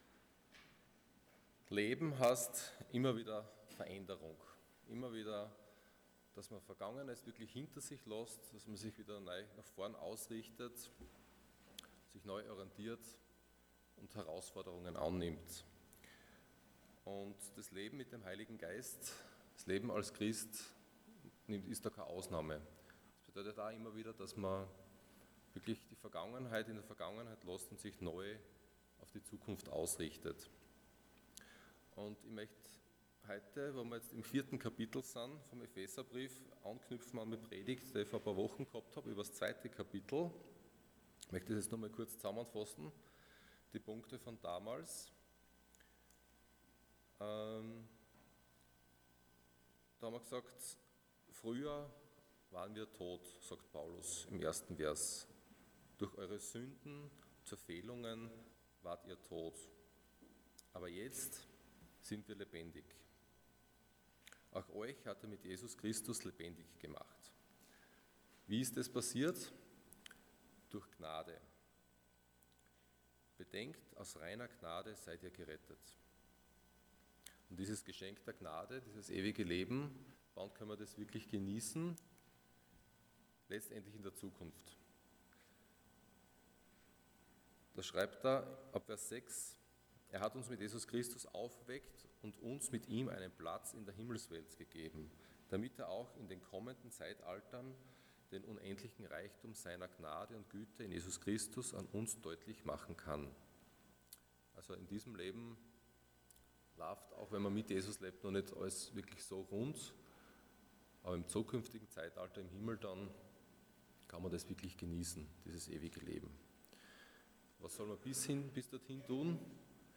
Passage: Ephesians 4:17-5:2 Dienstart: Sonntag Morgen